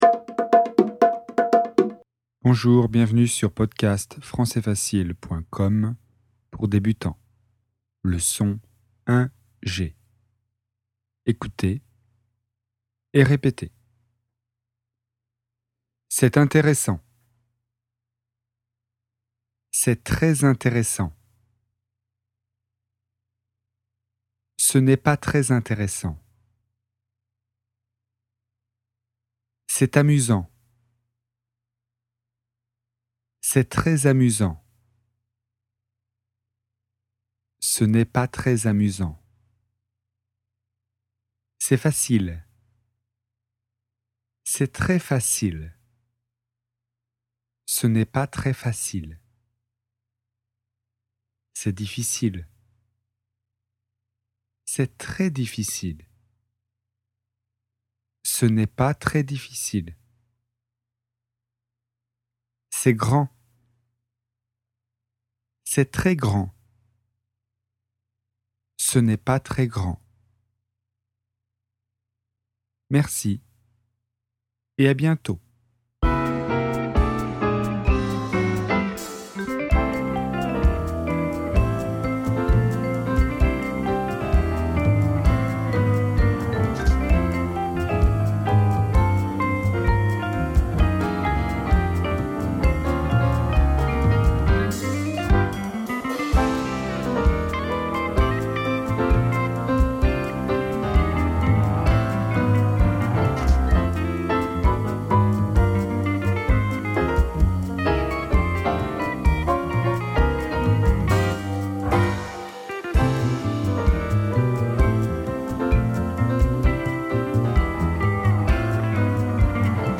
Leçon sur les premiers pas en français, niveau débutant (A1).